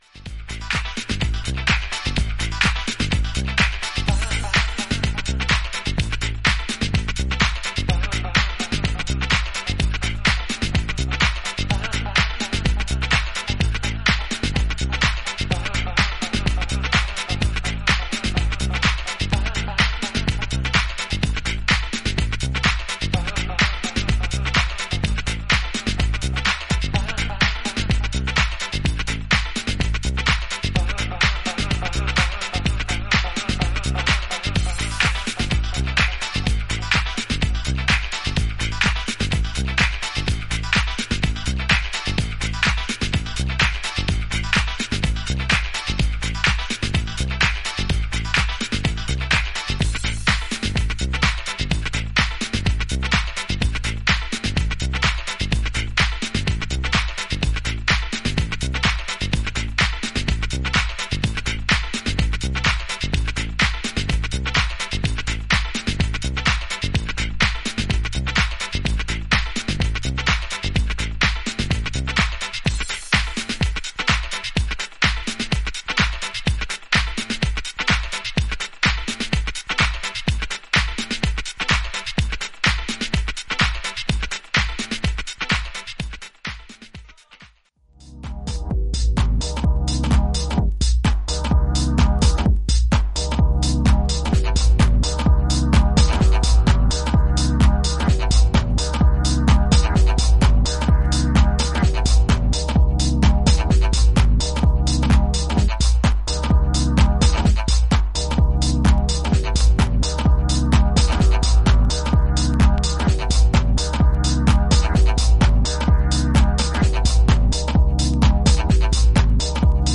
エッヂの効いたリズムにカット・アップ的に配置されたシンセのフレーズが印象的な